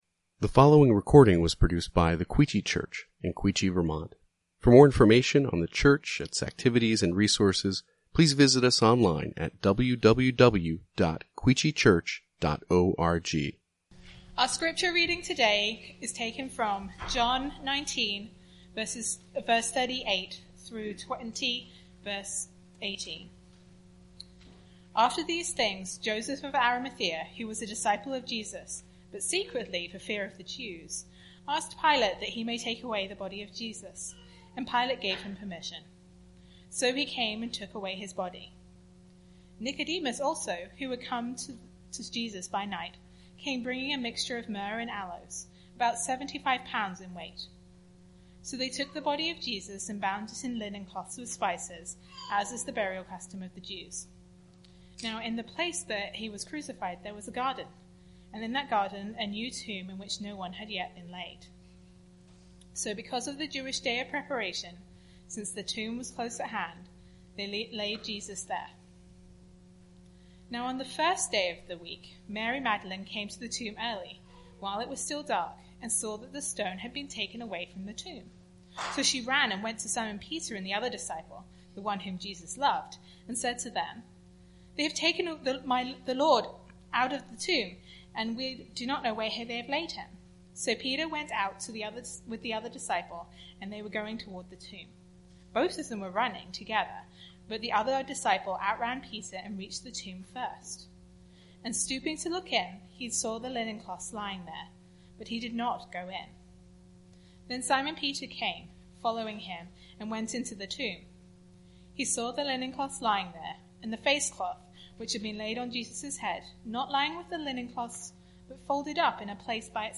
Quechee Church | Sermon Categories Sermon Series